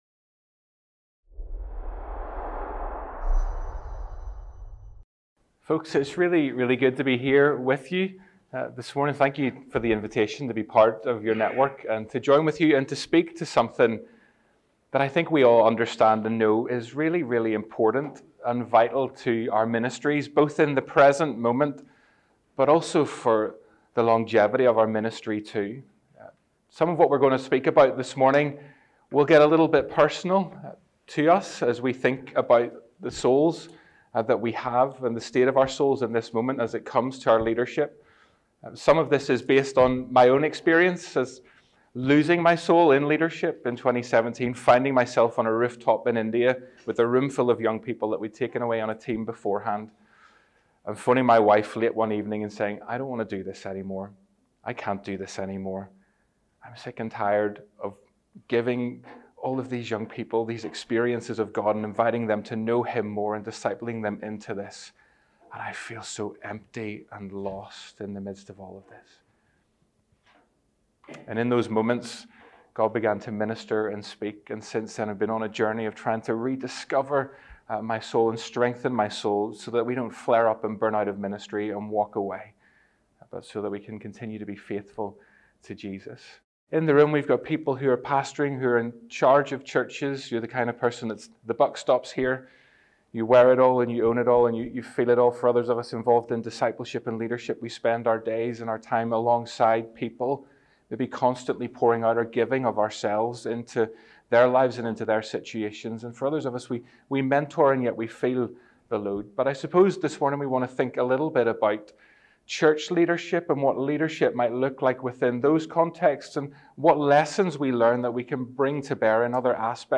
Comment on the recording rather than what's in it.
Event: ELF Soul Care Network